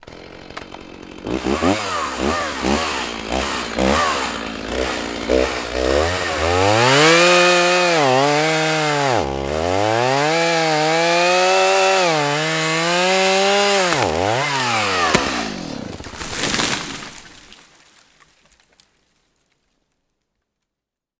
Lyd: Motorsag
Motorsag.mp3